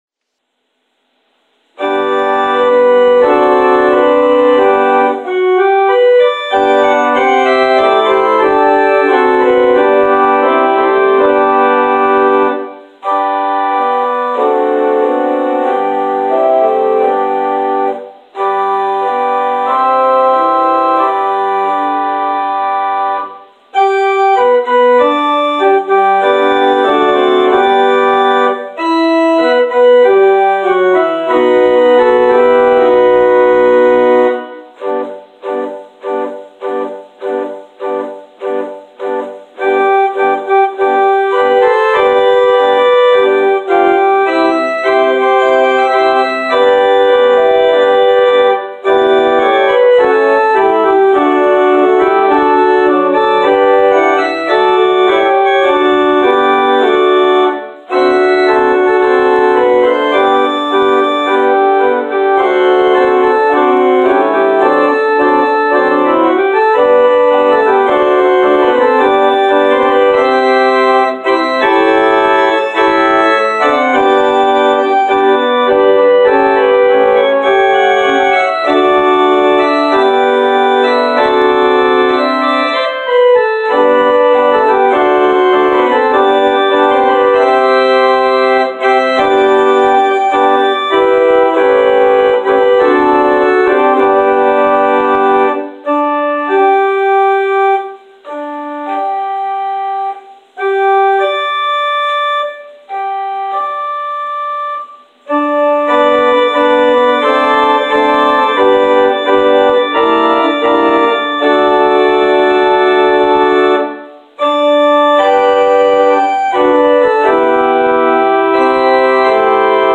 Organ preludes: